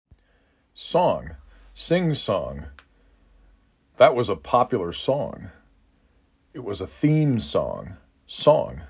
3 Phonemes
s aw N